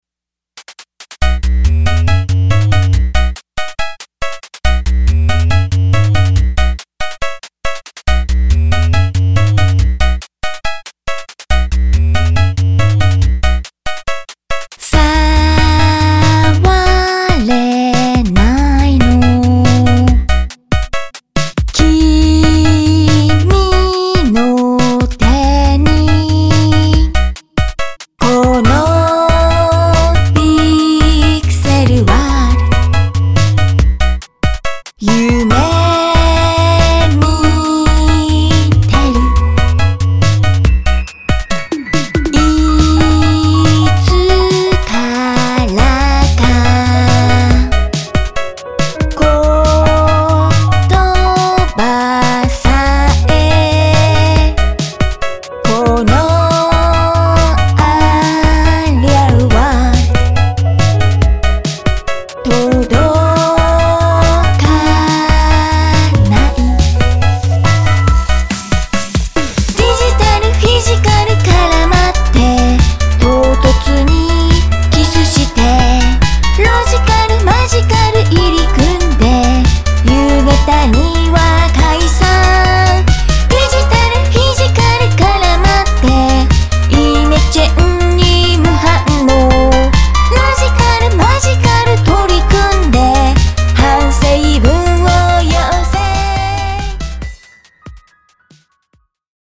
ファミコンサウンドとピアノの融合